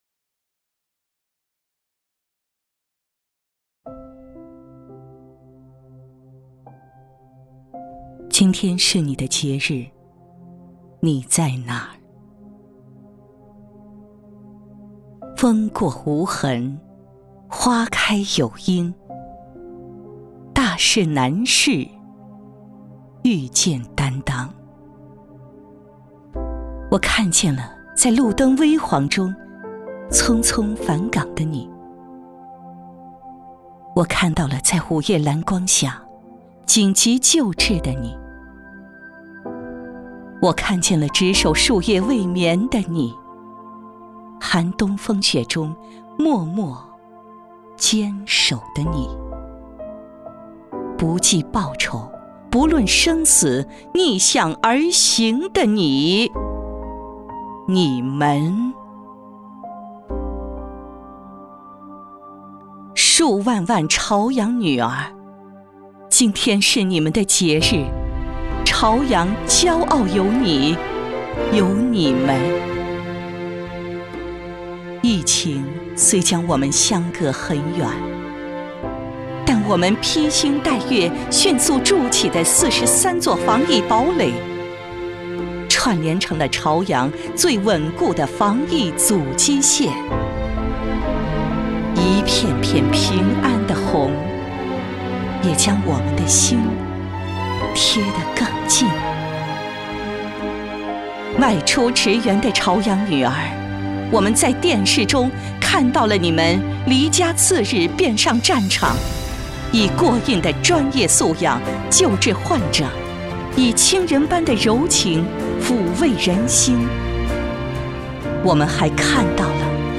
女国75_其他_朗诵_巾帼抗疫.mp3